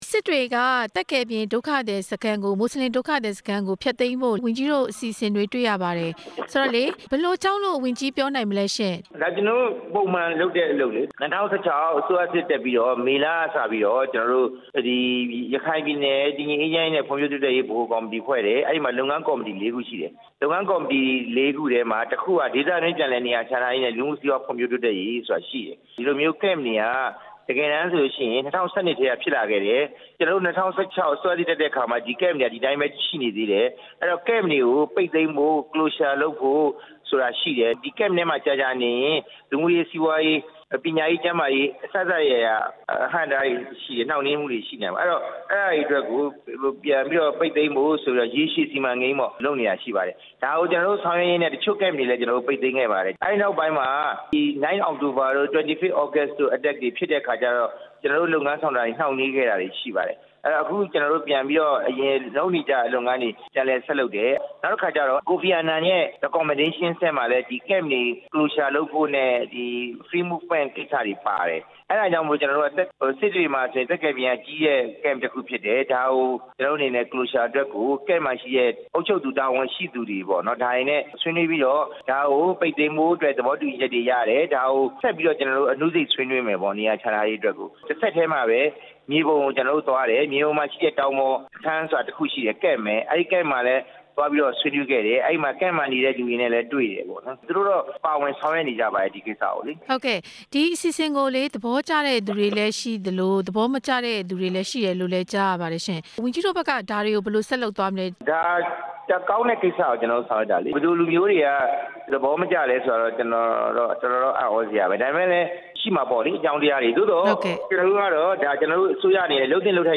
မွတ်စလင်ဒုက္ခသည်စခန်း ပိတ်သိမ်းရေးအစီအစဉ် ဝန်ကြီးနဲ့ မေးမြန်းချက်